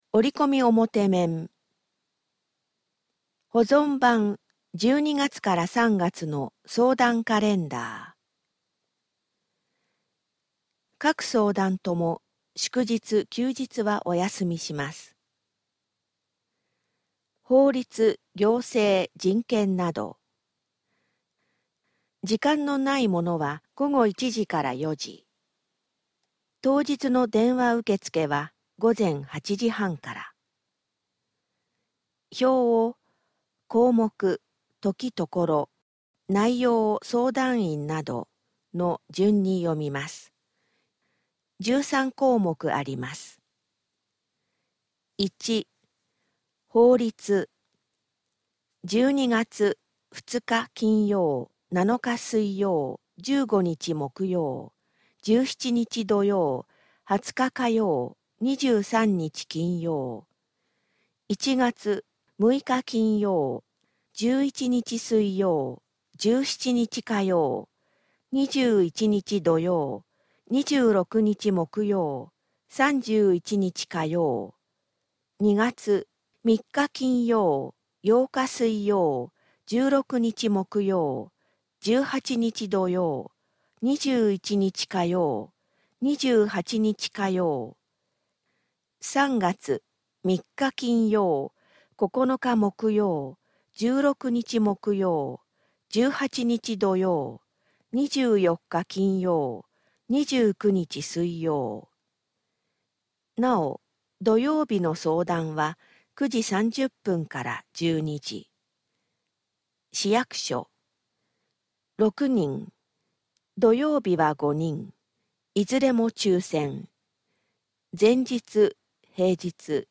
声の広報（概要版）